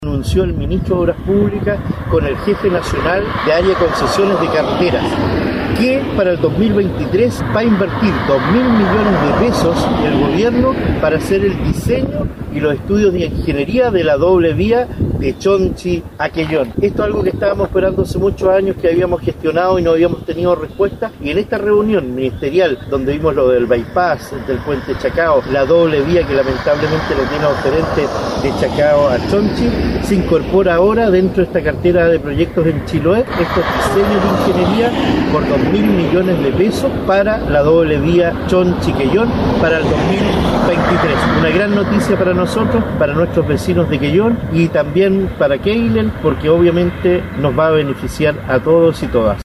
En tal sentido, alcalde de Quellón, Cristian Ojeda, manifestó que ya se están preparando las bases de licitación, para dar paso a un proceso de análisis que se extenderá hasta el 2025: